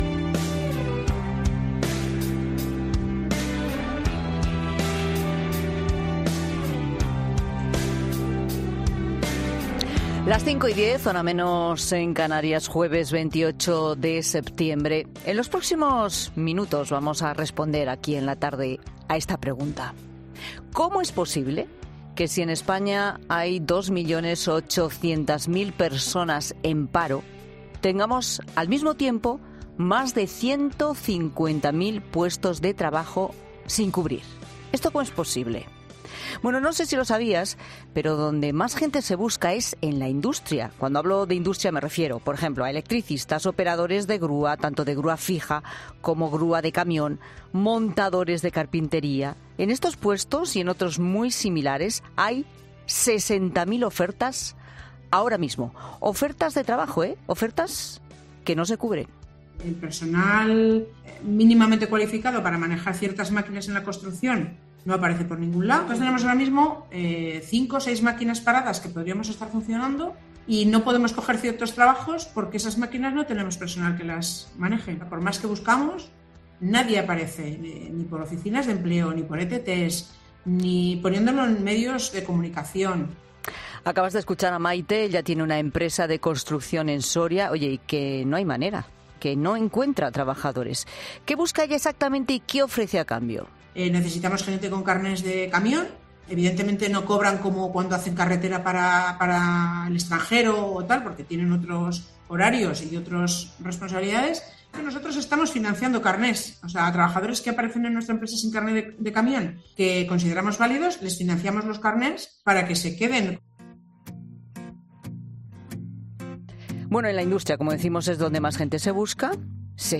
Escucha el reportaje completo sobre los parados en España